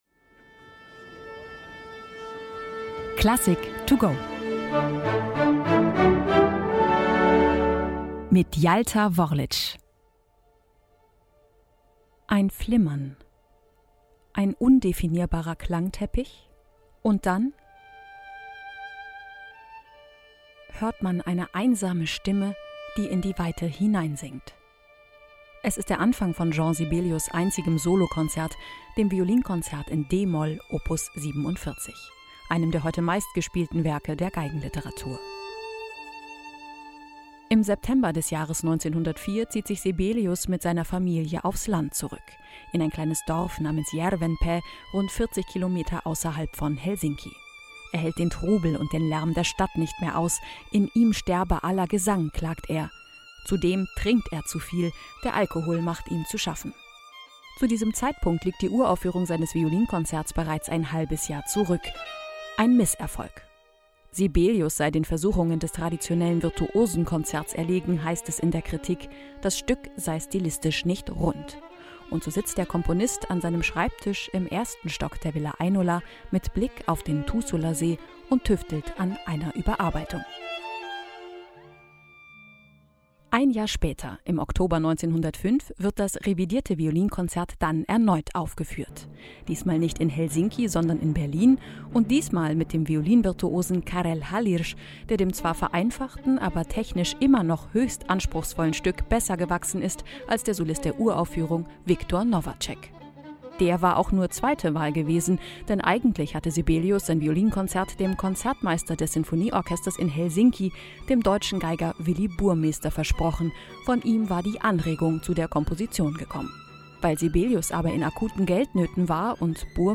in dieser Konzerteinführung zum Download.